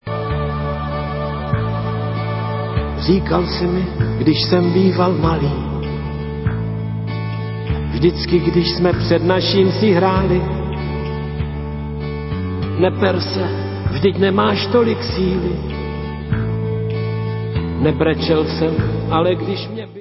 české pop-music